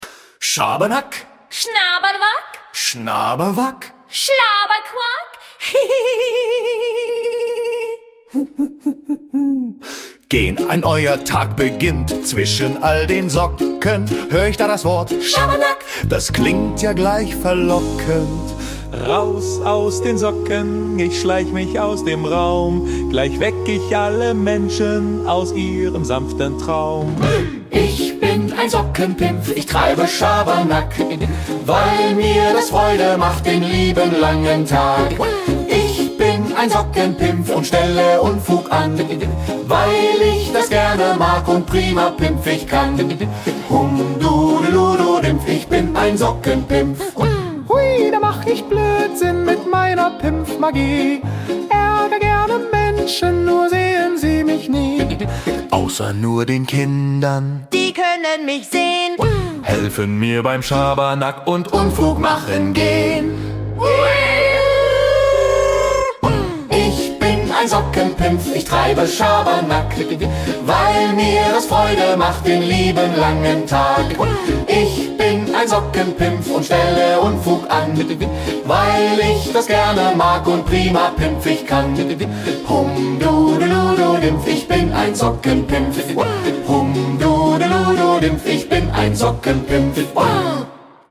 Ein kleines Sockenpimpfen-Lied.